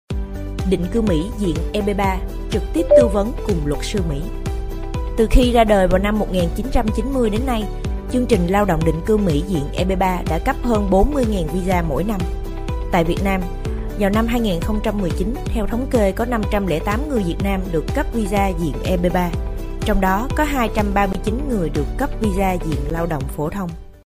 越南语样音试听下载
VN-DV010-female-demo.mp3